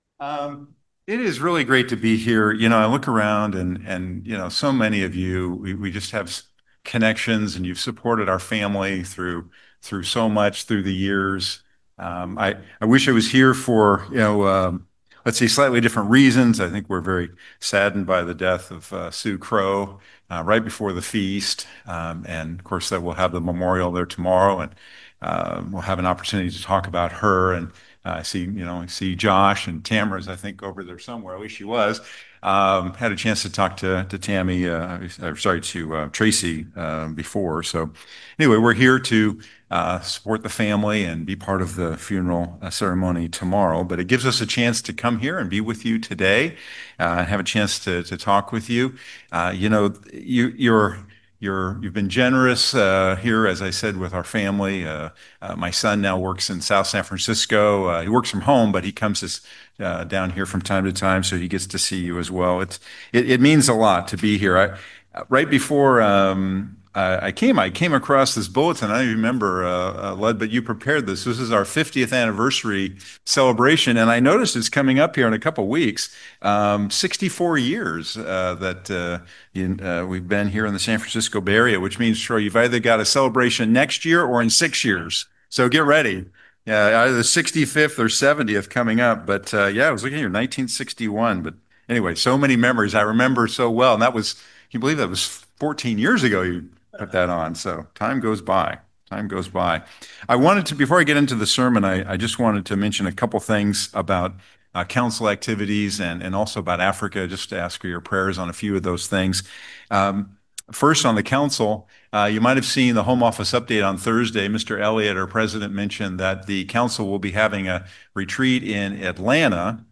Sermons
Given in San Francisco Bay Area, CA Petaluma, CA